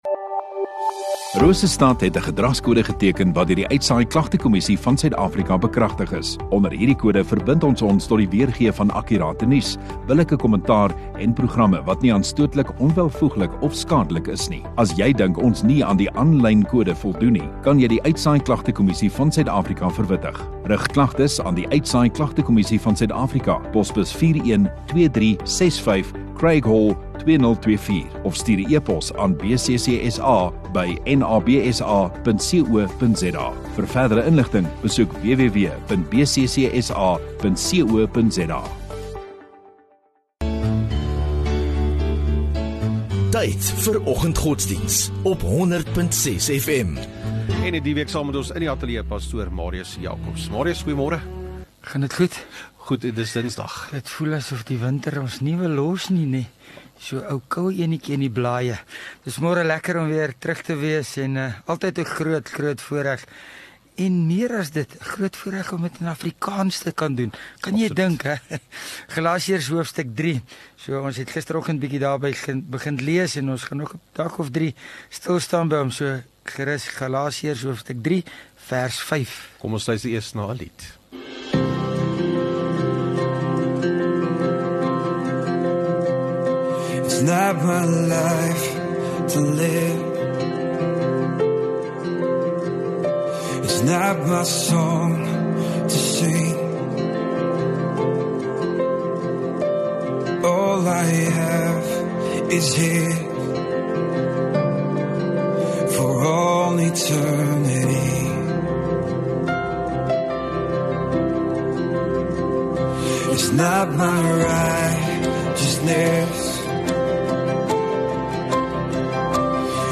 29 Oct Dinsdag Oggenddiens